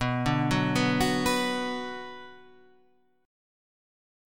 B Minor